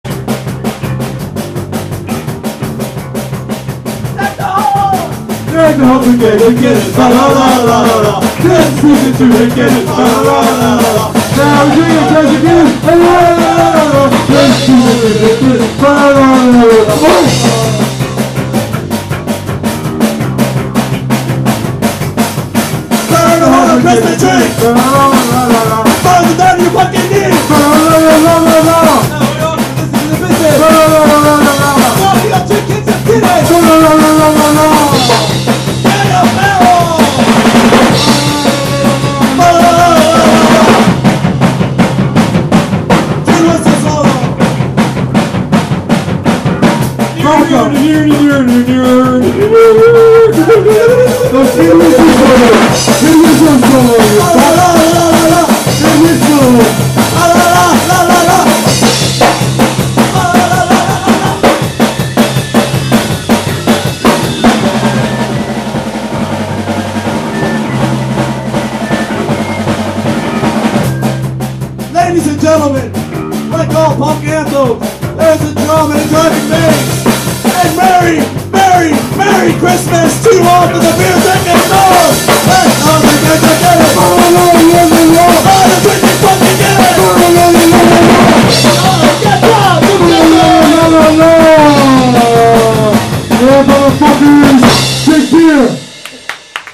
Christmas Songs!